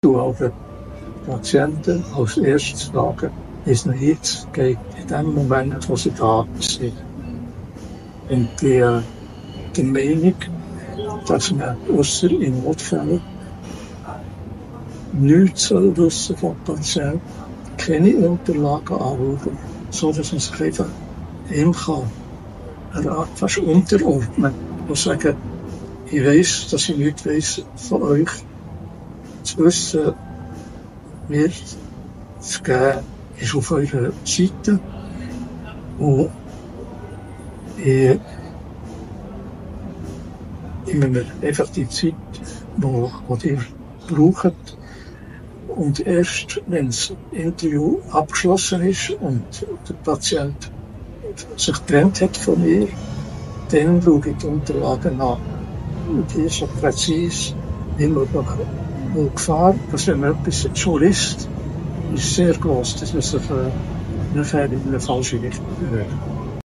durfte ich einen ganz besonderen Gast interviewen.